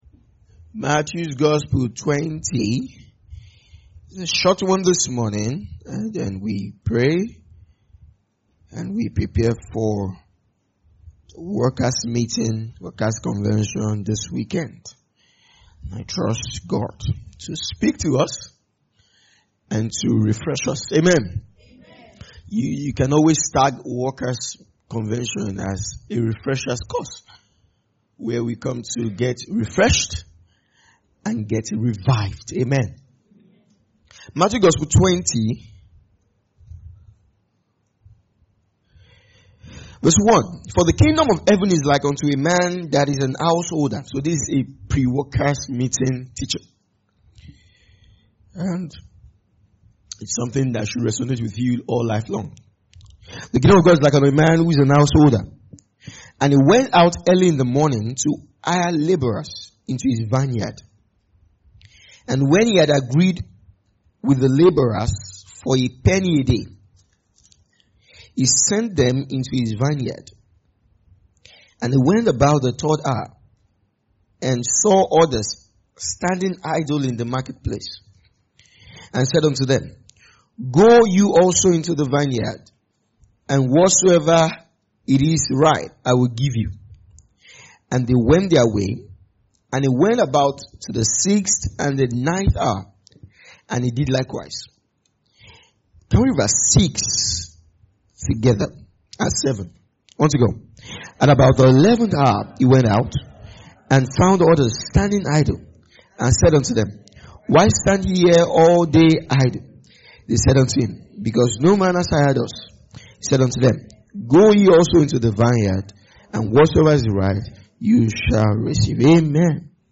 2025 Glorious House Church Teachings.